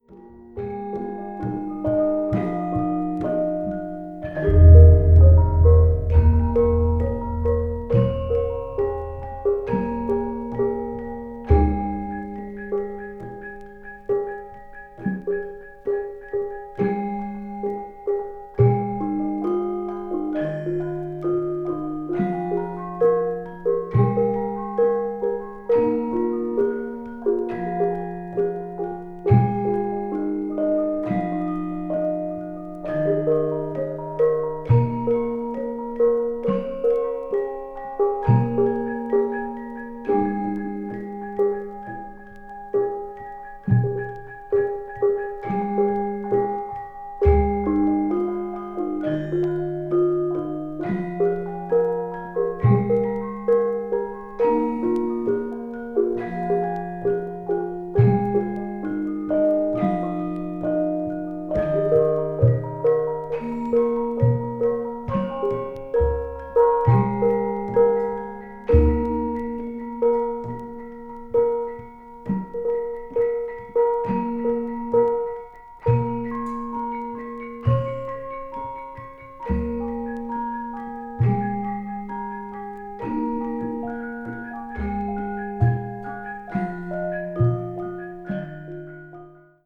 media : EX-/EX-(薄いスリキズによるわずかなチリノイズが入る箇所あり)